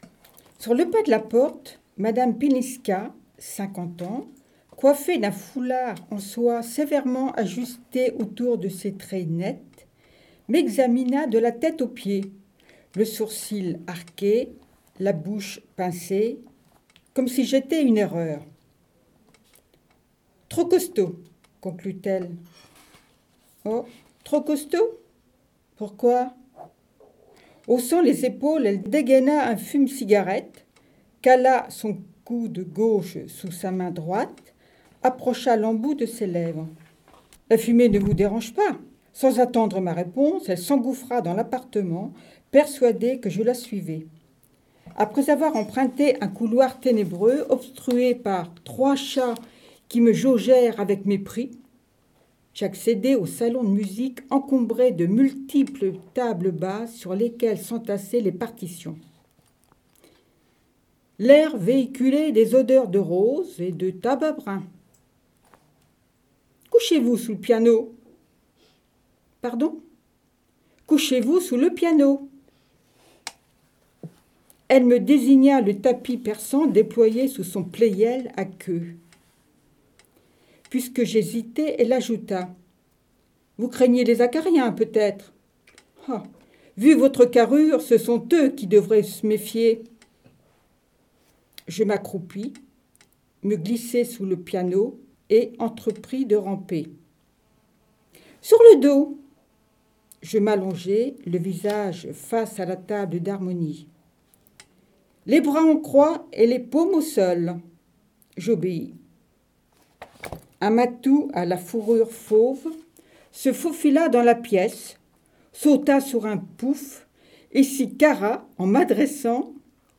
Les Haut Parleurs de l'association "Lire à Saint-Lô" prêtent leurs voix sur MDR dans l’ émission "Lire et délire" !